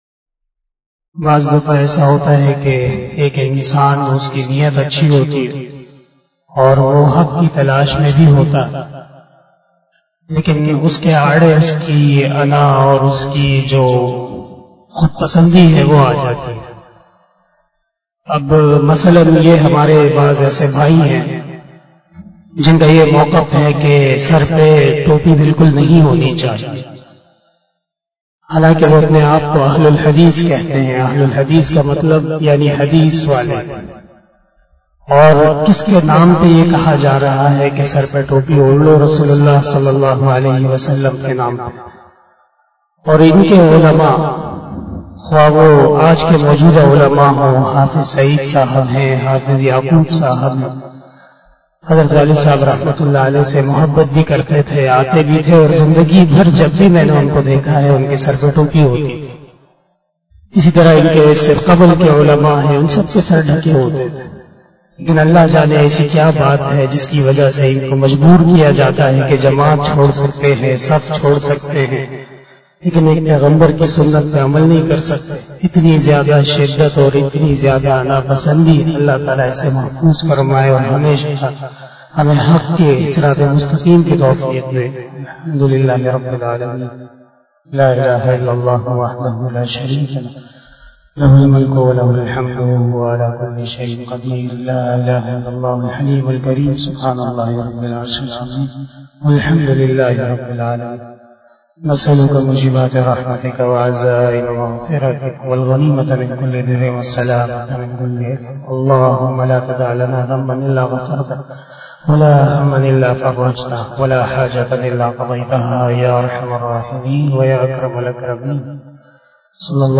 063 After Asar Namaz Bayan 30 September 2021 (22 Safar 1443HJ) Thursday